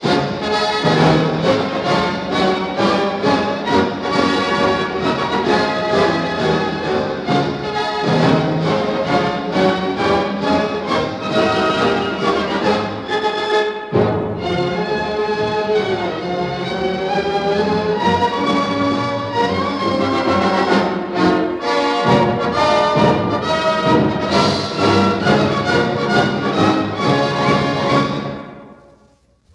구분조직 군가
1944년 보스턴 팝스(Boston Pops)가 연주한 "육군 항공군"